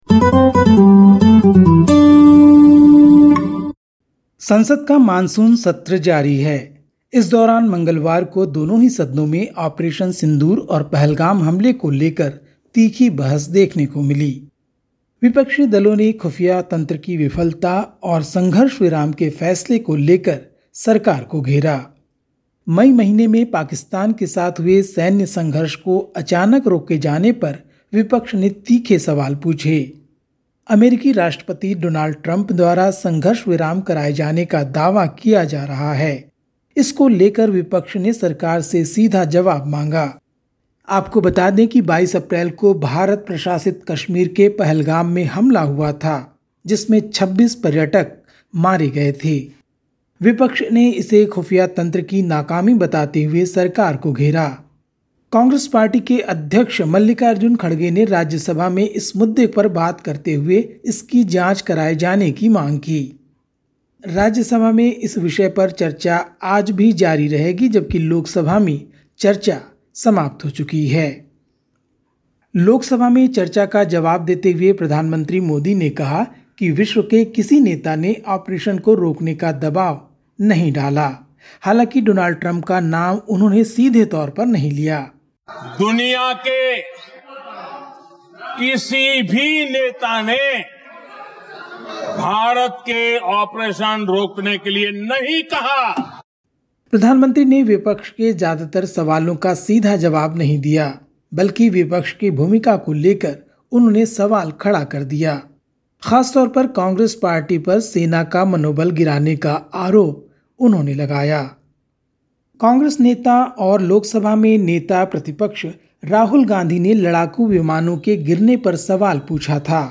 Listen to the latest SBS Hindi news from India. 30/07/25